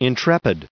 added pronounciation and merriam webster audio
455_intrepid.ogg